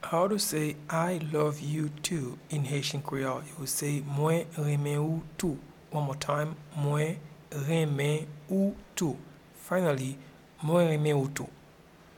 Pronunciation and Transcript:
I-love-you-too-in-Haitian-Creole-Mwen-renmen-ou-tou.mp3